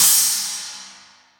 • Urban Crash Cymbal Single Shot F Key 05.wav
Royality free drum crash tuned to the F note. Loudest frequency: 8272Hz
urban-crash-cymbal-single-shot-f-key-05-Ukx.wav